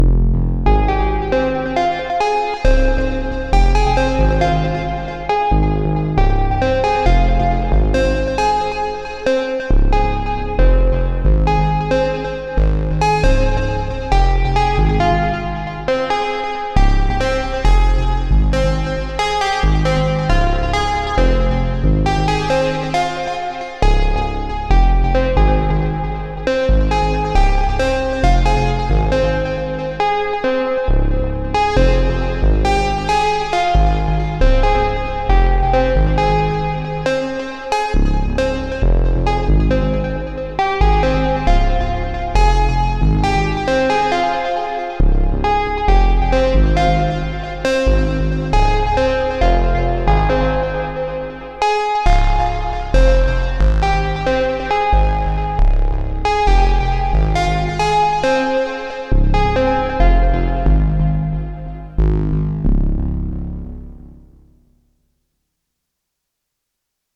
Got to spend some more time making patches this weekend, thought I’d upload some short recordings for anyone who wants to hear more sounds from this synth.